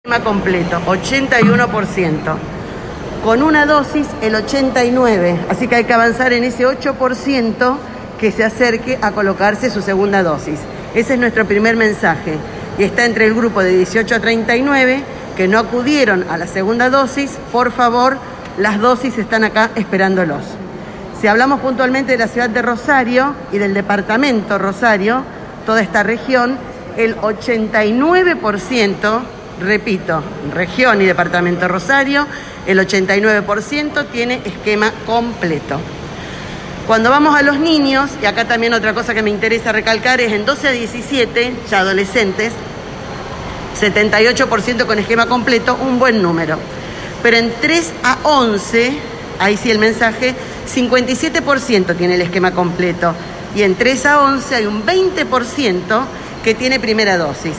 El 81% de los santafesinos ya tiene el esquema completo de vacunación, así lo confirmó este martes la ministra de Salud de Santa Fe, Sonia Martorano durante una conferencia en la Rural.